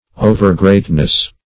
Overgreatness \O"ver*great"ness\, n. Excessive greatness.